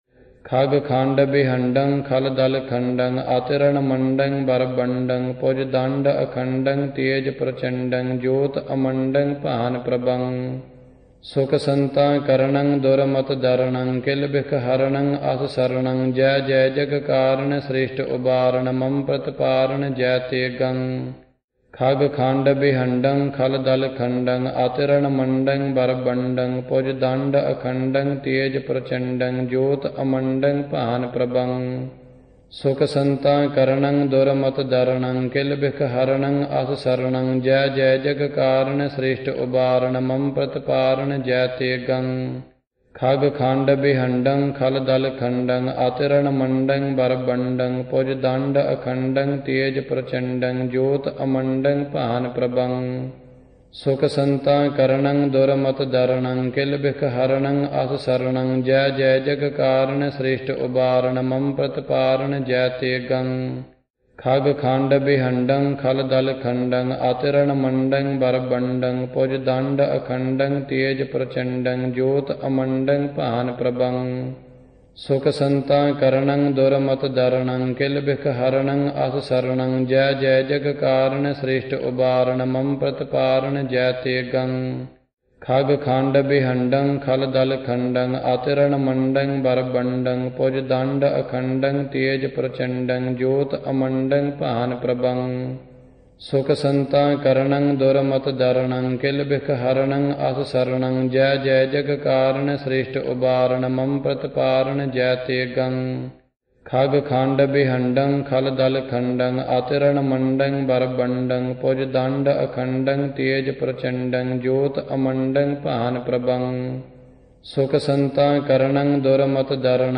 Você pode praticar com este áudio, junto com o Yogi Bhajan, entoando por 11 vezes:
01-Jai-Te-Gang-recitation.mp3